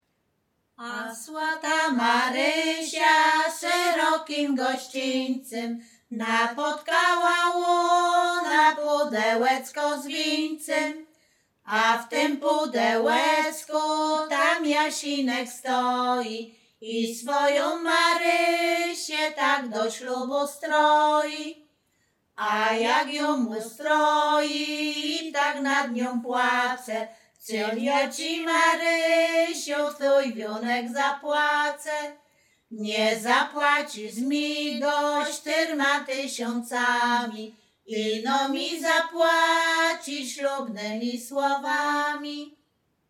Śpiewaczki z Chojnego
województwo łódzkie, powiat sieradzki, gmina Sieradz, wieś Chojne
liryczne miłosne weselne